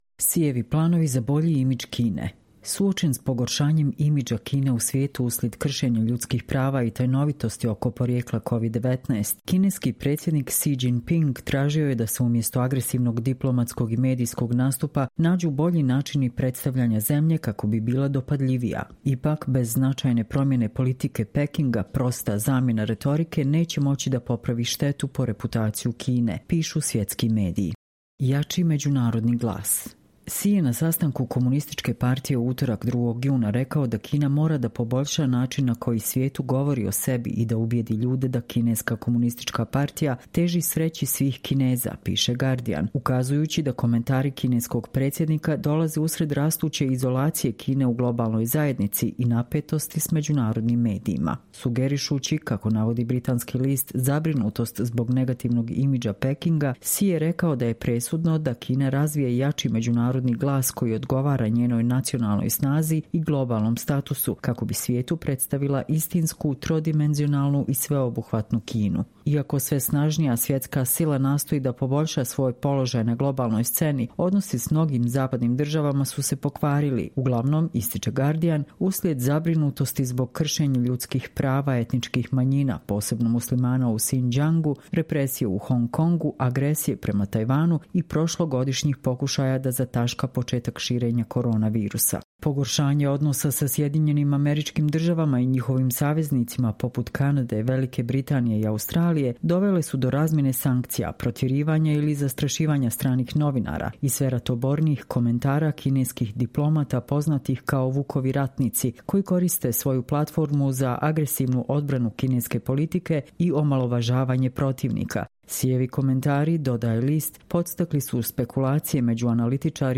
Čitamo vam: Sijevi planovi za bolji imidž Kine